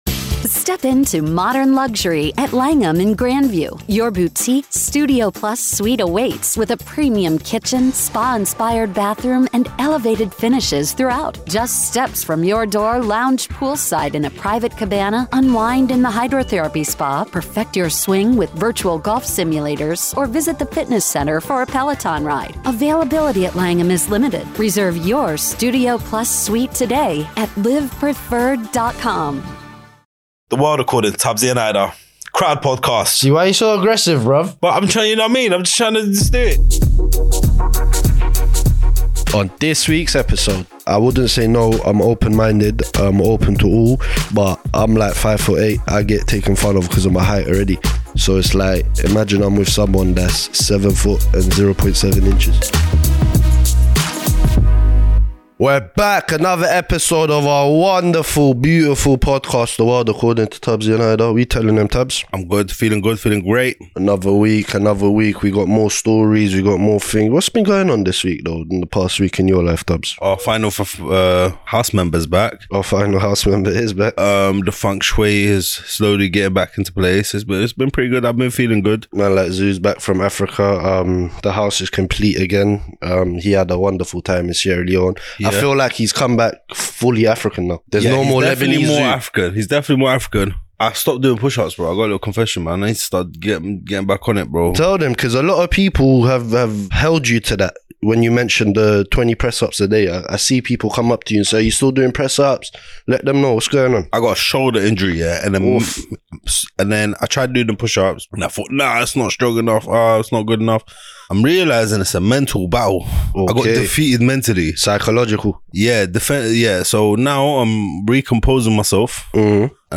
This week the boys dive into some particularly interesting stories. From bombs and hospitals to unusual world records, the pair sit down to look at the weird and wonderful. So pull up a seat and join the conversation.